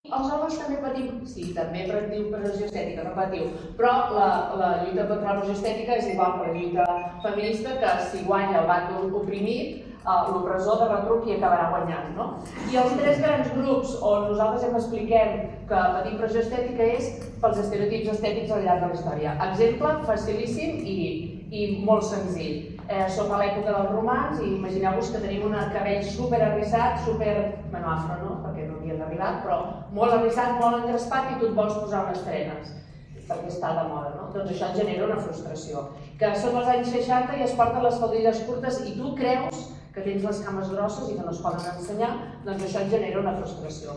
La Sala Albéniz ha acollit aquest dissabte 7 de març a les dotze del migdia l’acte institucional del 8M a Tiana, que enguany ha posat el focus en la pressió estètica i el cos.